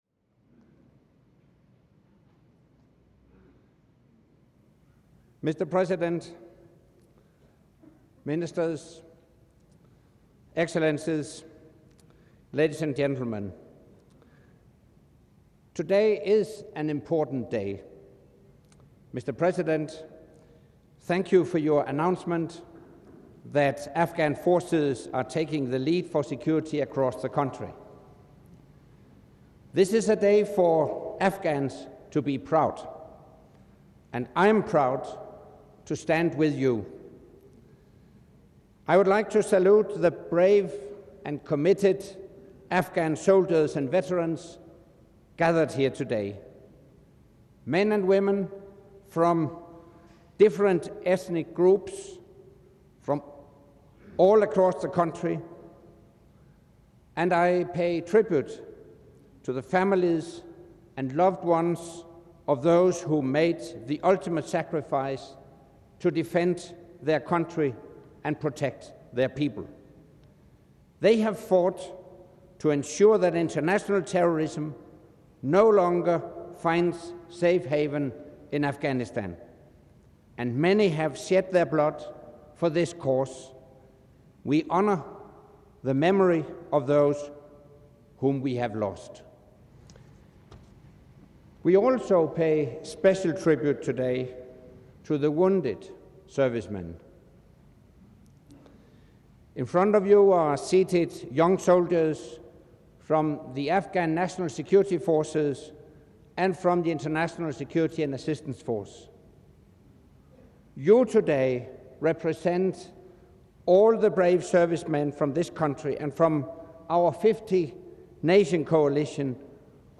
''Marking the milestone in Kabul'' - Speech by NATO Secretary General Anders Fogh Rasmussen at the ceremony in Kabul, Afghanistan